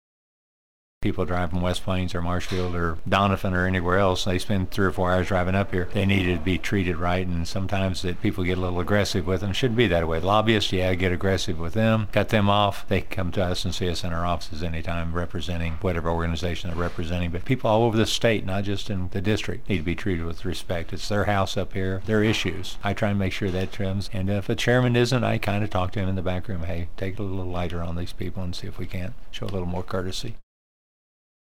2. Senator Cunningham adds committee hearings allow people the opportunity to testify on legislation.